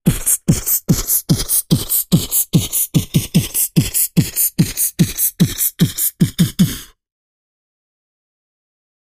Human Beat Box, Dance Beat